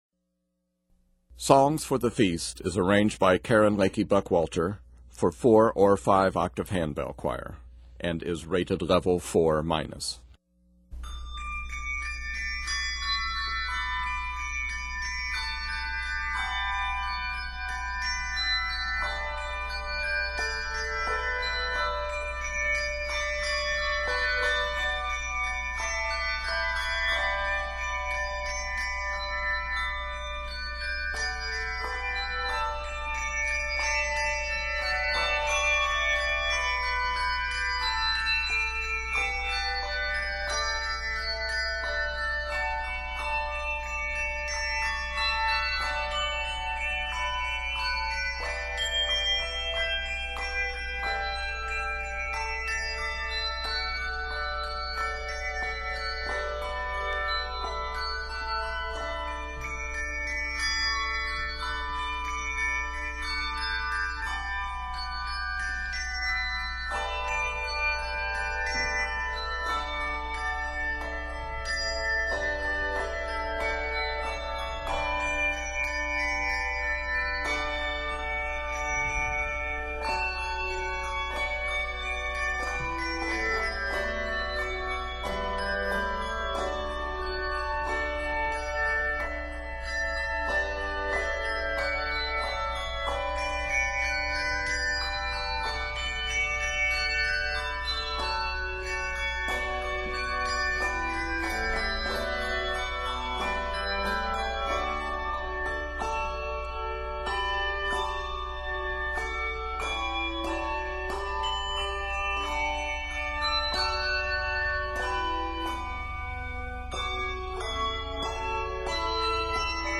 Communion hymns set in this medley